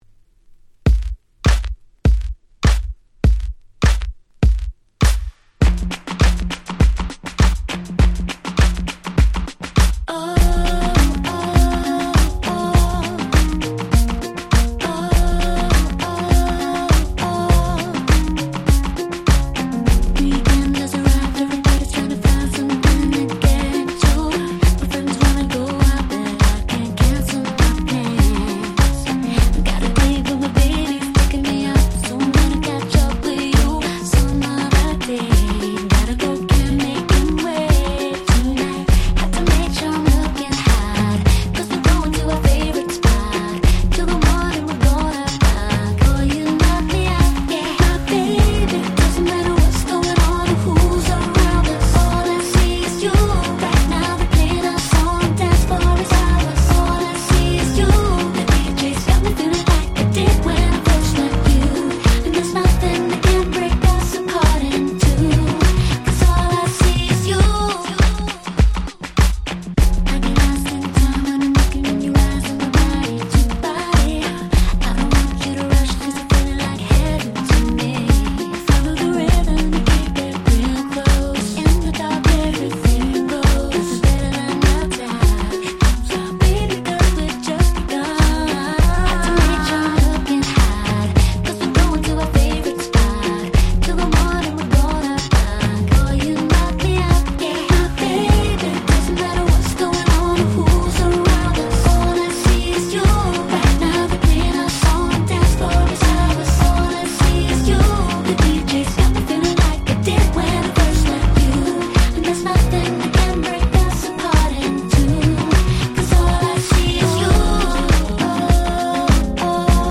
07' Super Nice R&B !!
キラキラでめちゃ良い曲！！